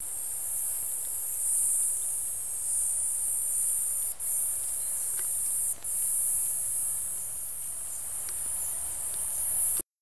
Cigale noire Cicadatra atra